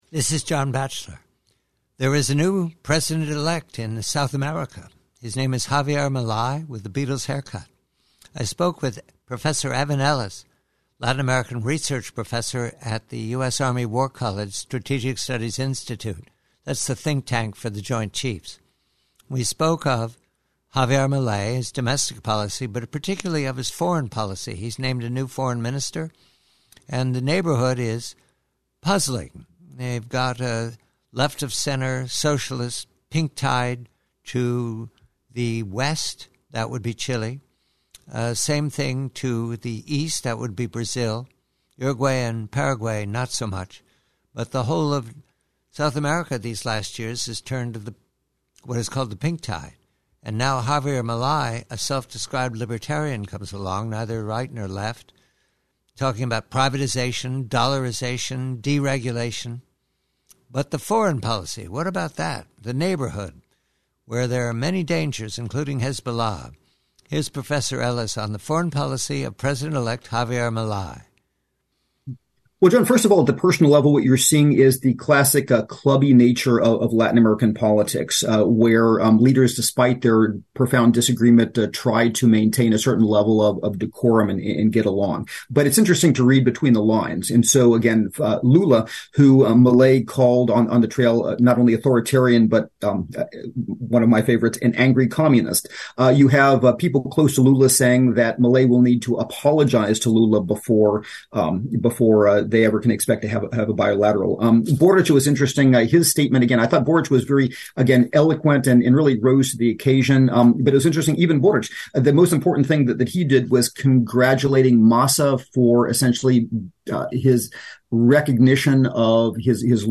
PREVIEW: From a much longer conversation